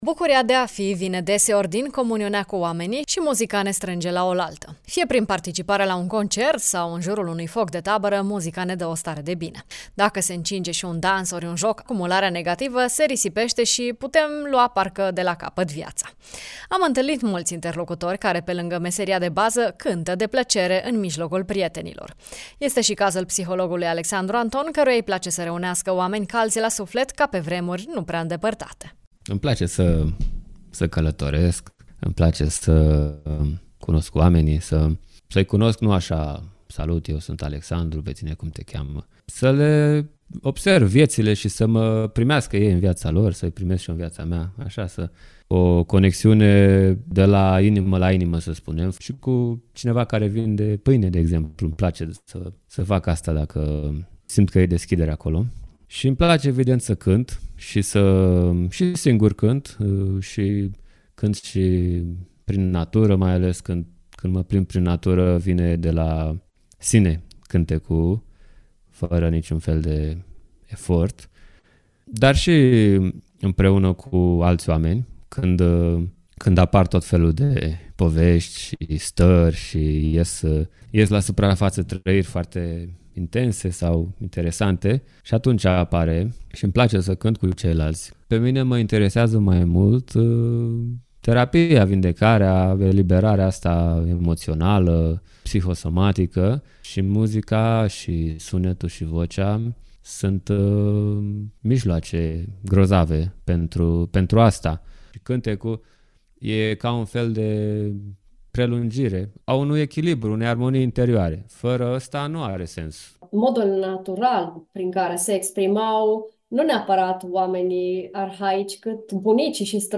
psiholog, muzician: